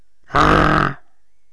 bull_select2.wav